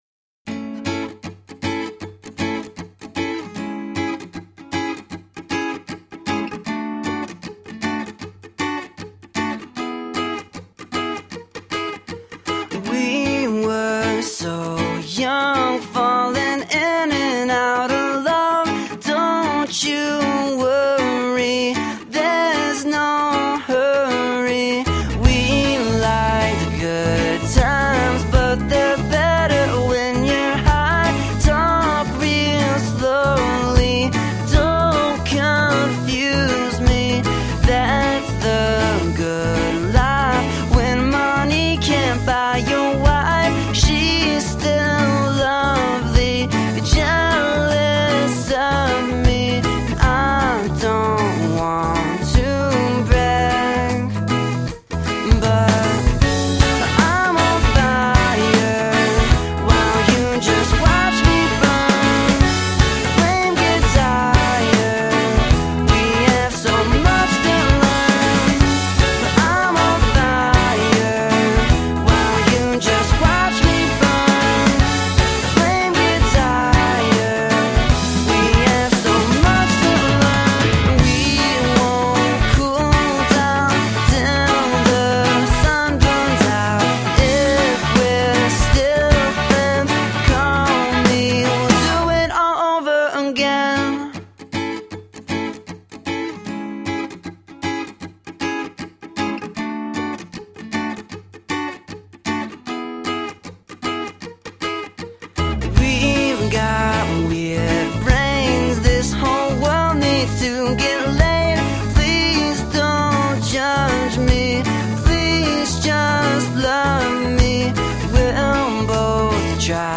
Guitar driven alternative rock.
Tagged as: Alt Rock, Rock, Folk-Rock, Indie Rock